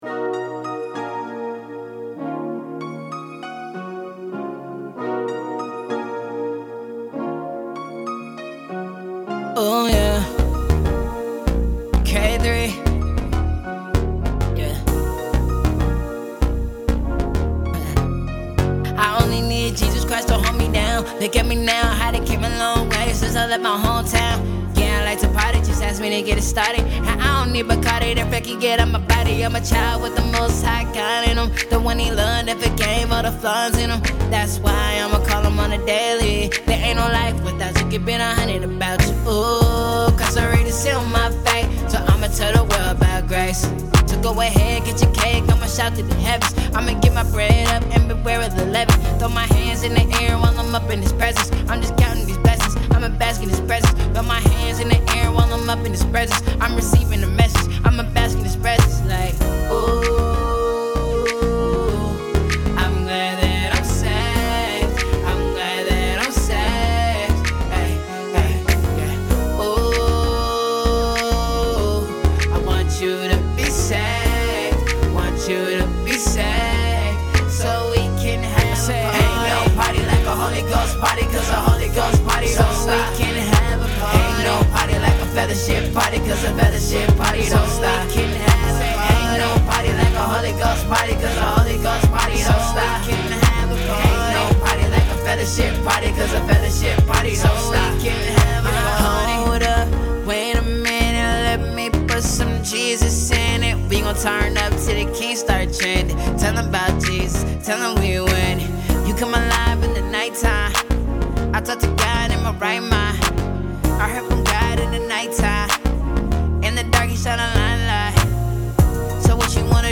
and pursue his calling in Christian Hip hop.